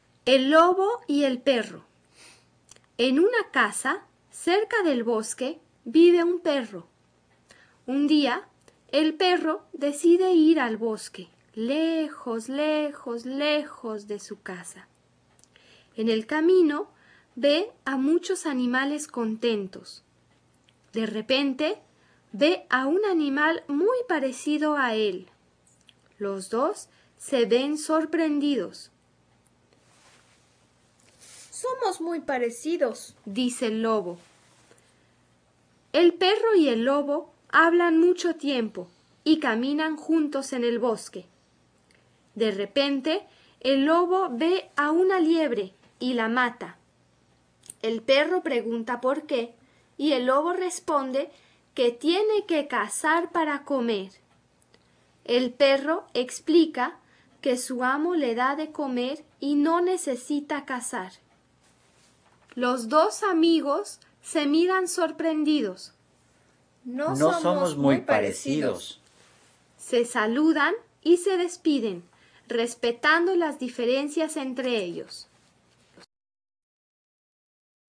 Lectura - Cuento para niños...